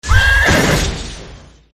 horse_jump.mp3